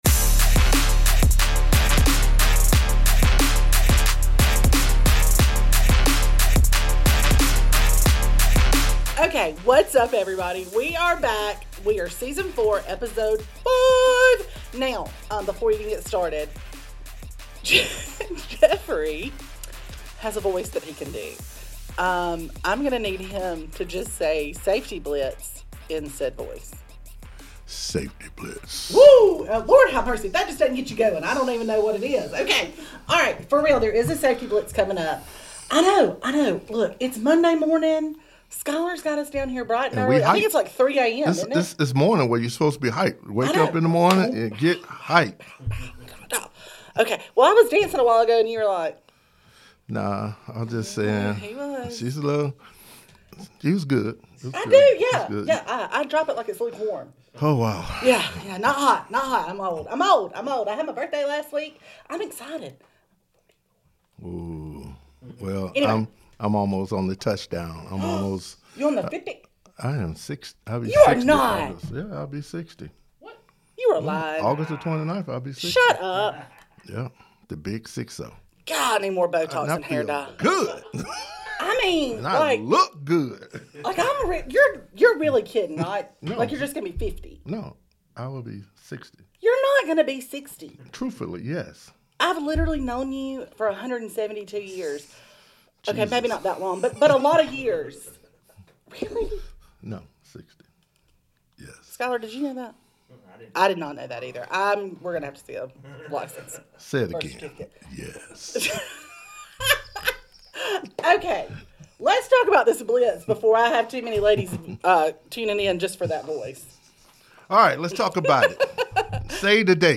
Tune in to hear members of Boyd discussing the trucking industry and highlighting the amazing drivers and team members at Boyd Brothers Transportation!